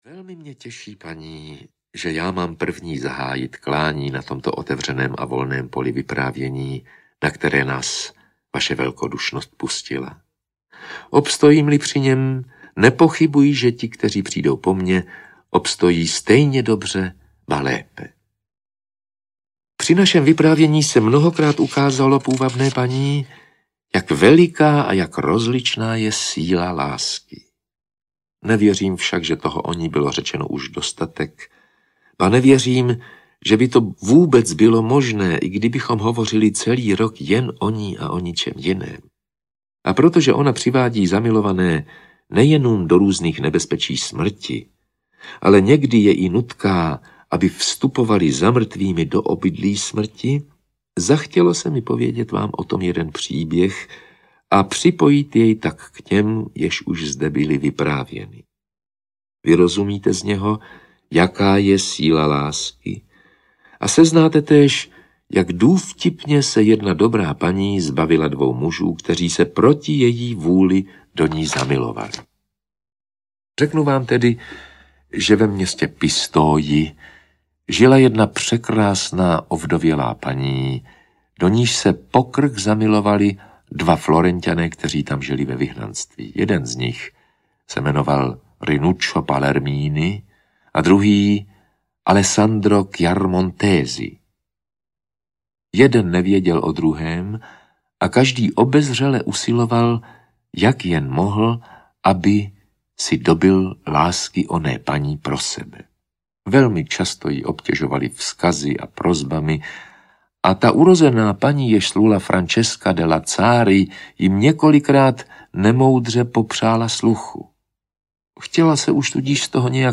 Dekameron - Den devátý audiokniha
Ukázka z knihy
• InterpretRudolf Pellar